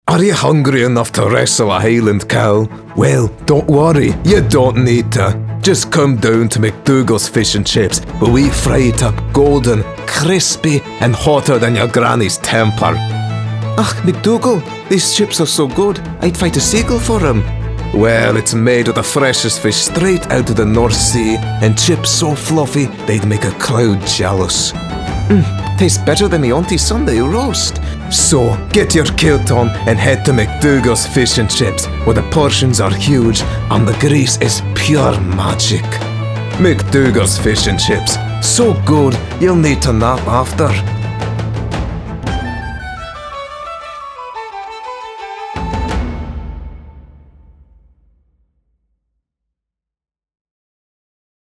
authoritative, character, confident, Deep, Quirky, smooth
Scottish Accent